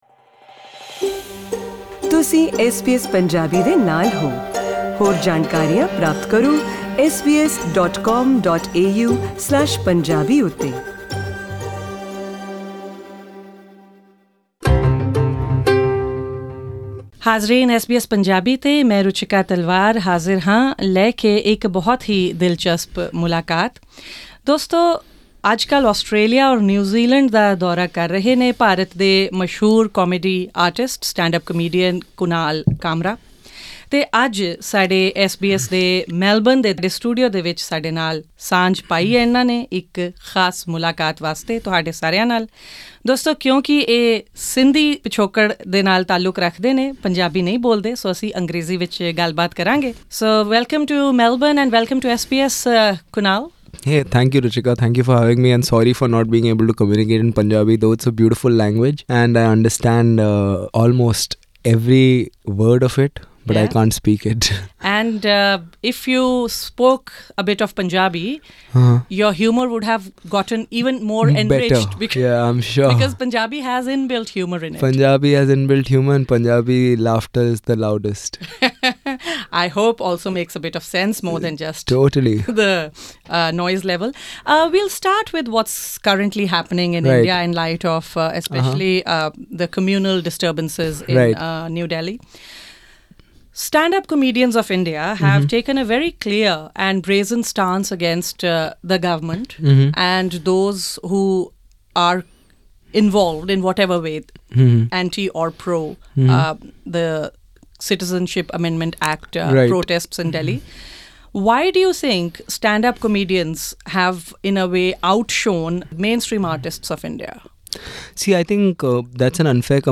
Popular Indian stand-up comedian and political activist, Kunal Kamra talks about the triggers for his art and politics and the insecurities of being famous on social media.